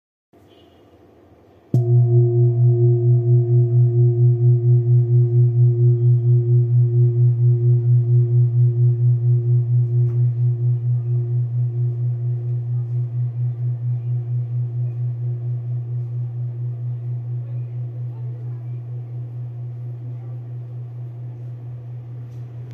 Old Hand Beaten Bronze Kopre Singing Bowl with Antique
Material Bronze